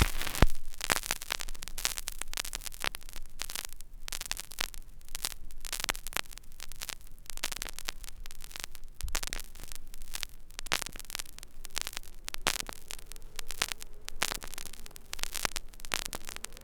VINYL1    -L.wav